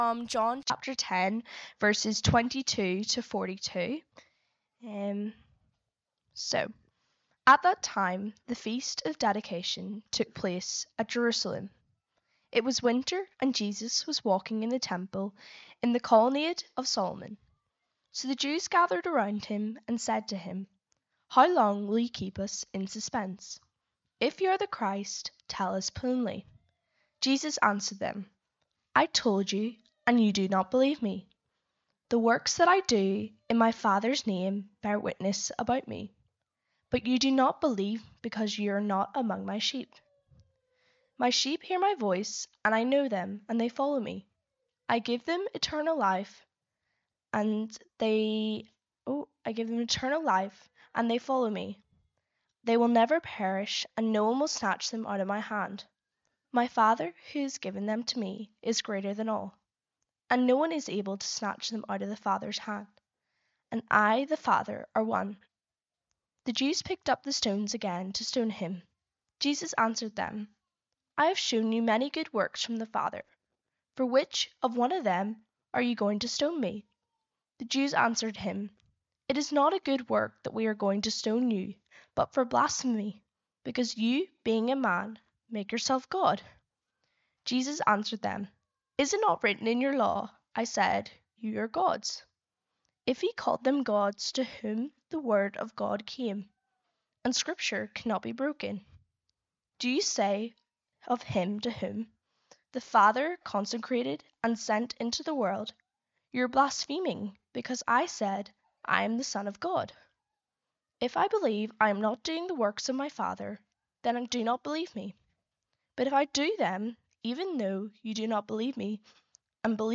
Sermons - The Bridge Church Strabane